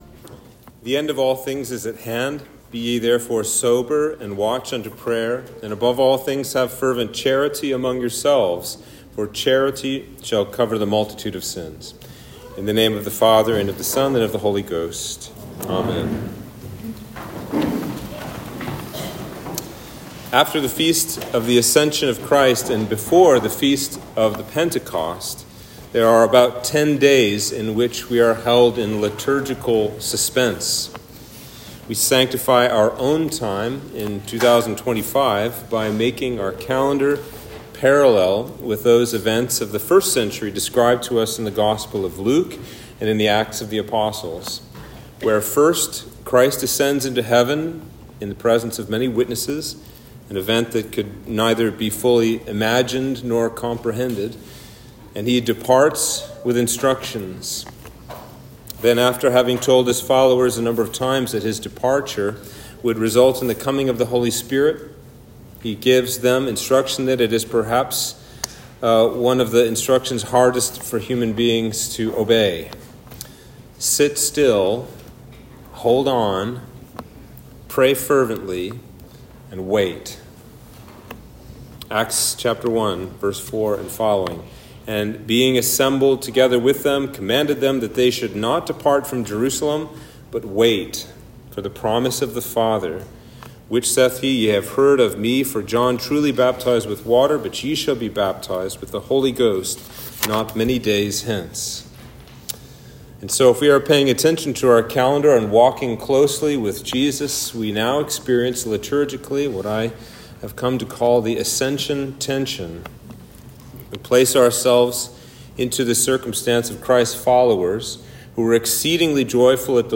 Sermon for Sunday After Ascension